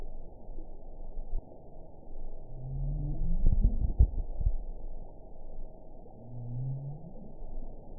event 914736 date 10/29/22 time 04:20:49 GMT (2 years, 6 months ago) score 6.86 location INACTIVE detected by nrw target species NRW annotations +NRW Spectrogram: Frequency (kHz) vs. Time (s) audio not available .wav